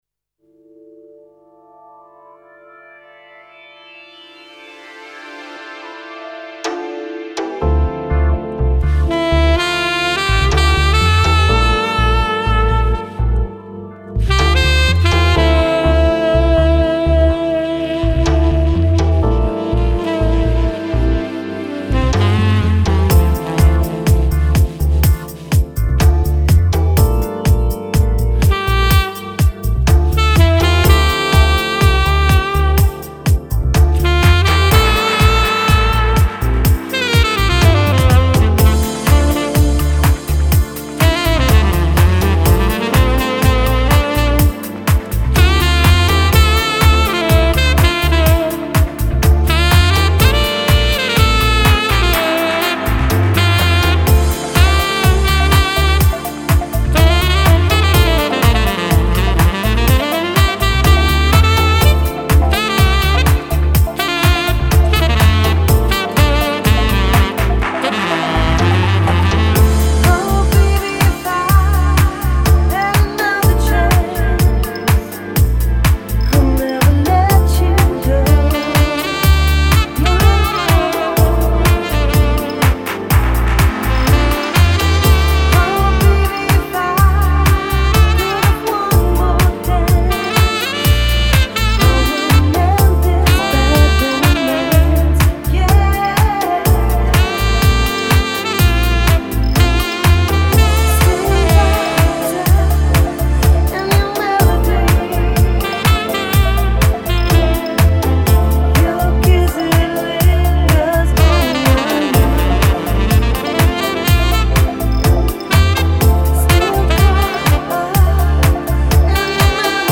SAXOPHONE BOSSA